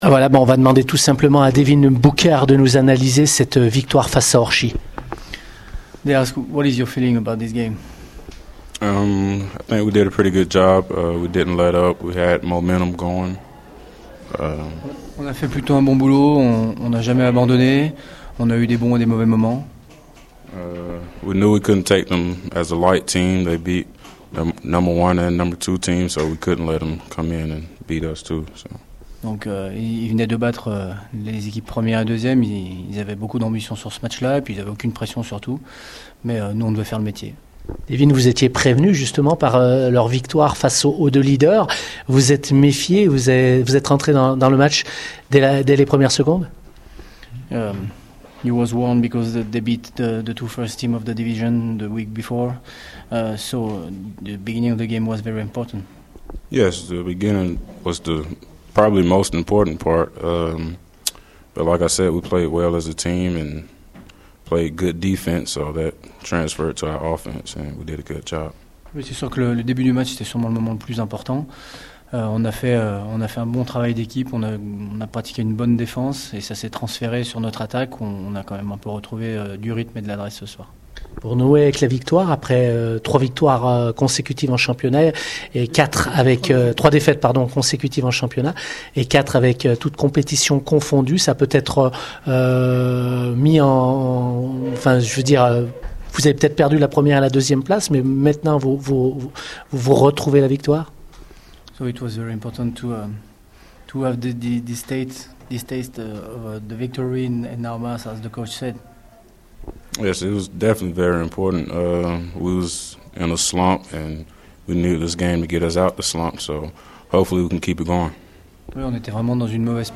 Retrouvez les réactions d’après-match au micro Radio Scoop